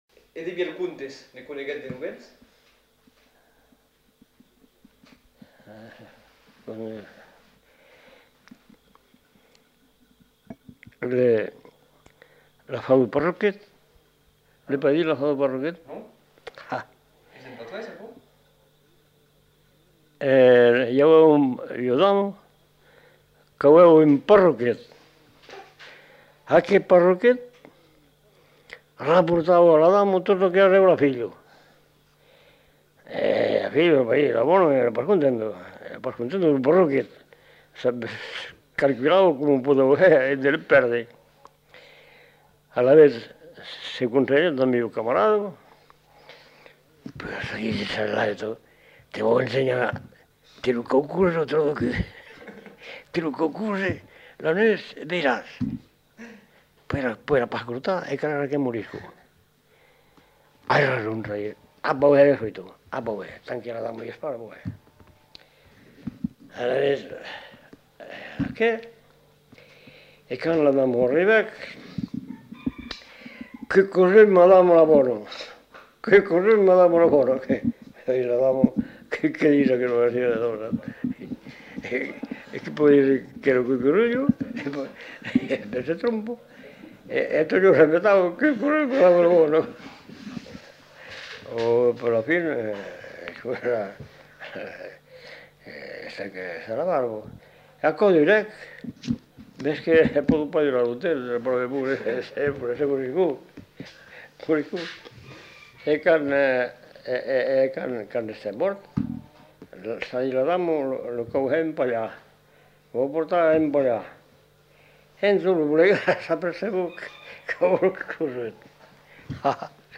Lieu : Faudoas
Genre : conte-légende-récit
Effectif : 1
Type de voix : voix d'homme
Production du son : parlé